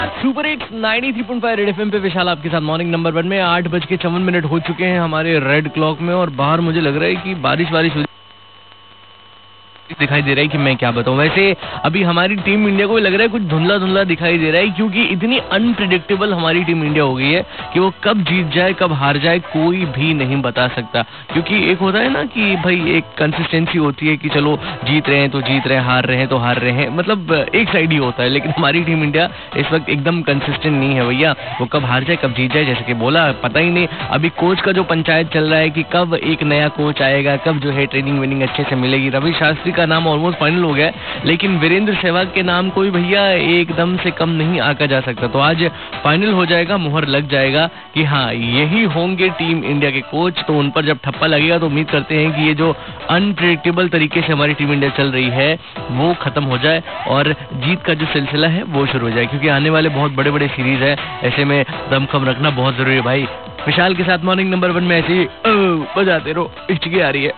RJ TALKING ABOUT CRICKET